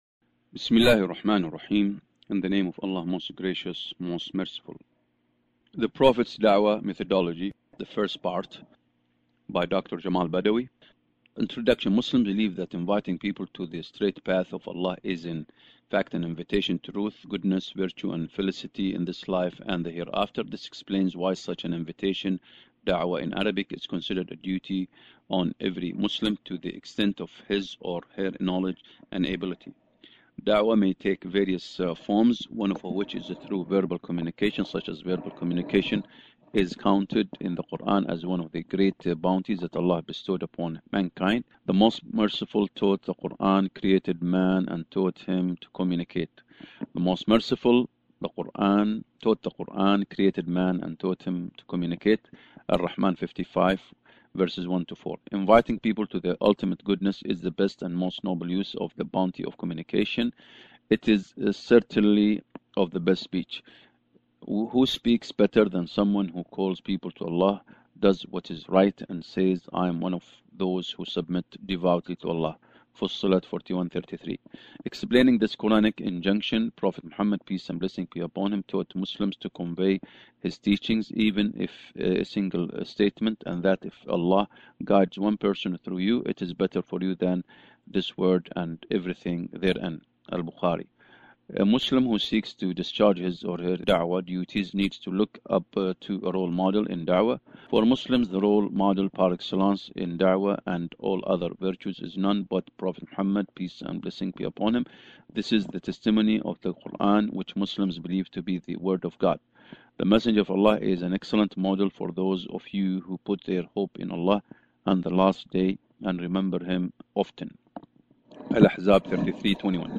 اسم المادة: قراءة صوتية لسلسلة مقالات للدكتور جمال بدوى